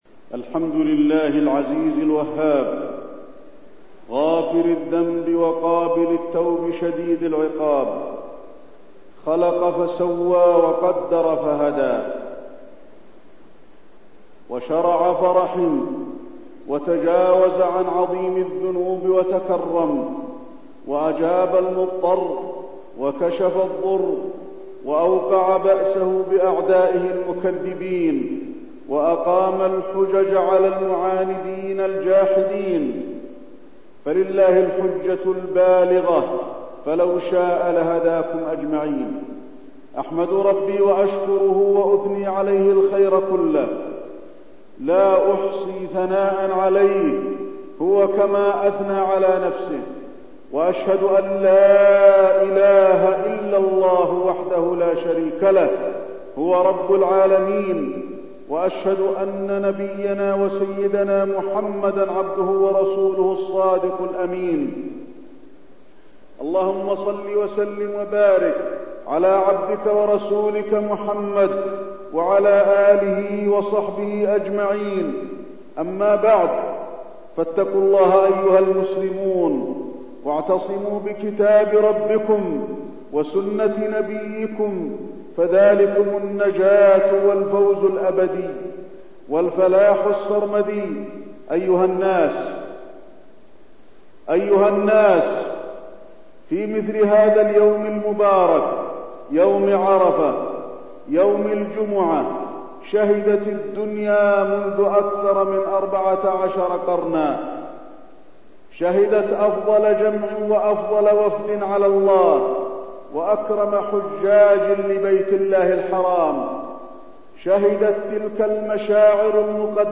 تاريخ النشر ٩ ذو الحجة ١٤١١ هـ المكان: المسجد النبوي الشيخ: فضيلة الشيخ د. علي بن عبدالرحمن الحذيفي فضيلة الشيخ د. علي بن عبدالرحمن الحذيفي يوم عرفة